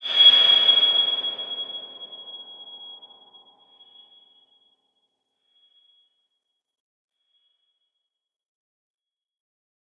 X_BasicBells-G#5-ff.wav